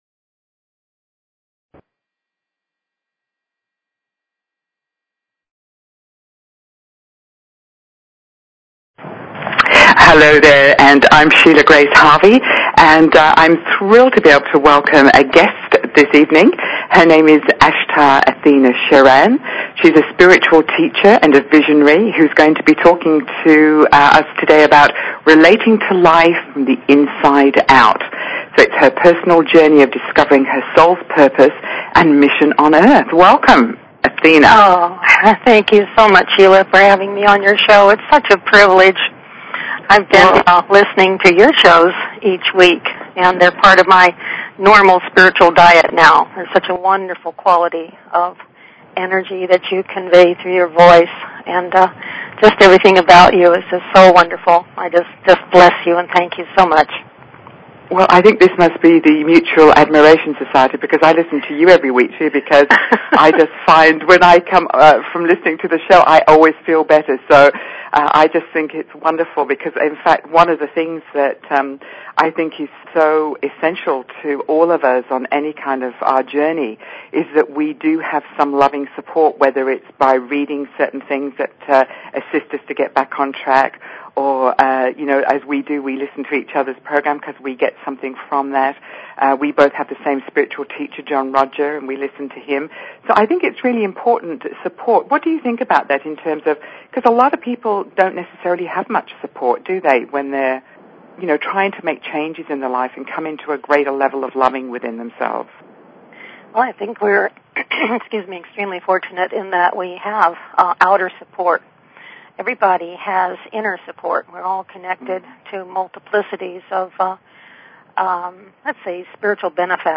Talk Show Episode, Audio Podcast, Relationship_Revelations and Courtesy of BBS Radio on , show guests , about , categorized as
This fascinating and often hilarious interview will delight you
She also guides us through an inspiring meditation to assist us to come back into greater balance.